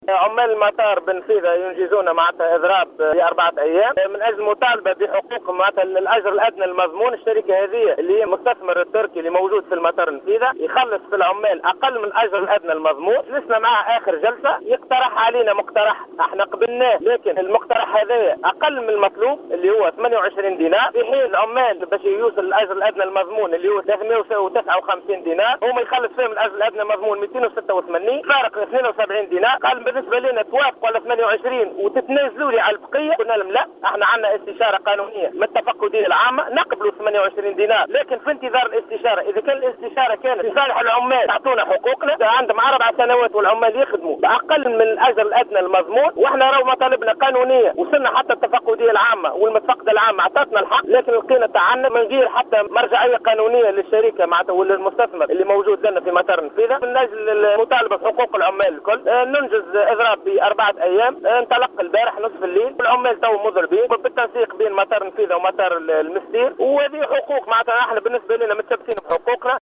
Cette décision a été prise après que la société TAV qui exploite l'aéroport ait refusé de prendre en considération les revendications des employés de l'aéroport, qui réclament le salaire minimum interprofessionnel garanti (SMIG).Dans une déclaration accordée à Jawhara FM